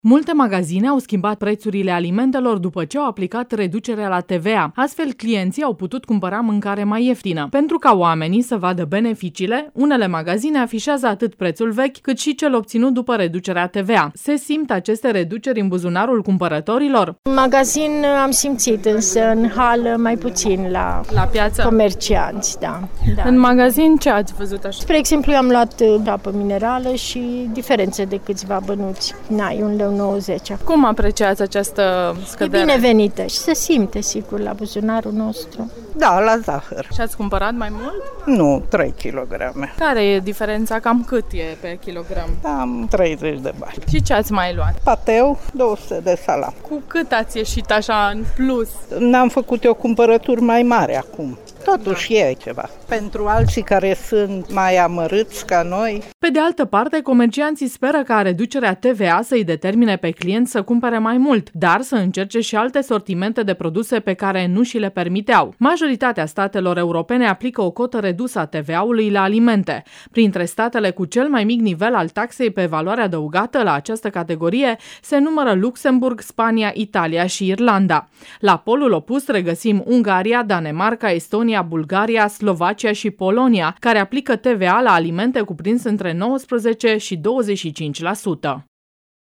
Unii reșițeni simt deja în buzunare reducerile, chiar dacă acestea nu sunt foarte mari: vox-tva-2 Majoritatea statelor europene aplică o cotă redusă a TVA-ului la alimente.
vox-tva-2.mp3